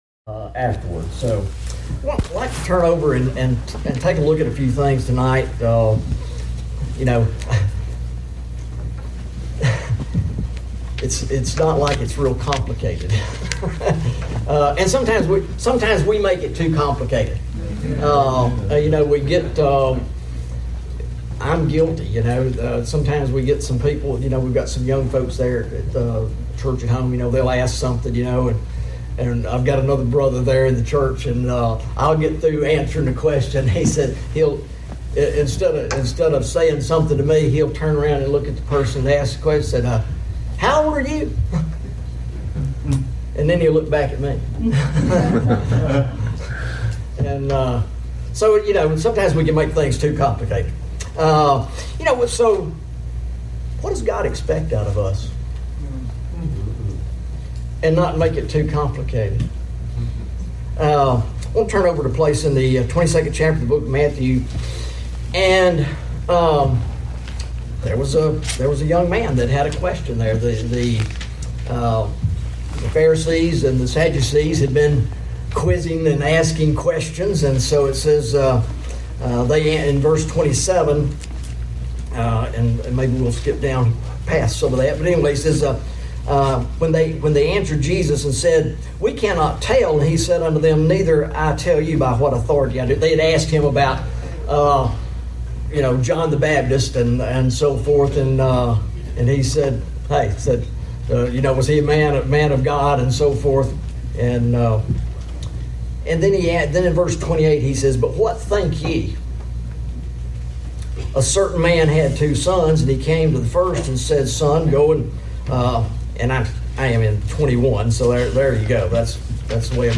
Posted in Little River August 2023 Meeting